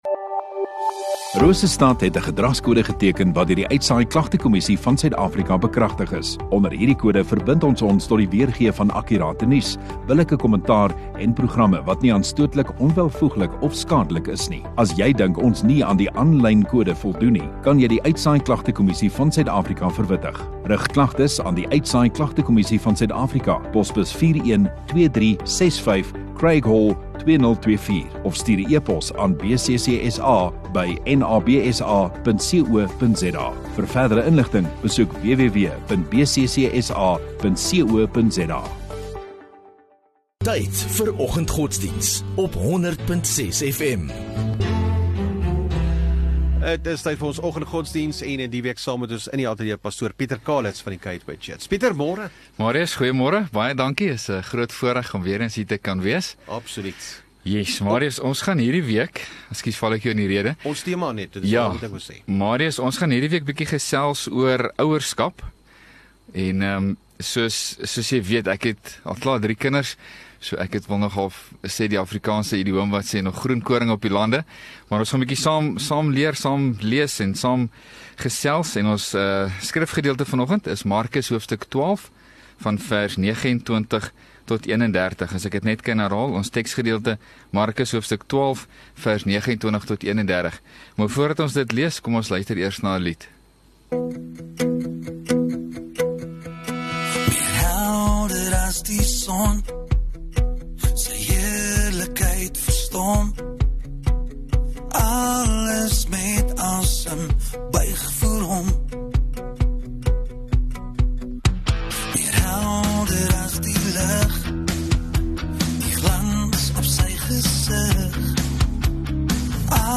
27 Jan Maandag Oggenddiens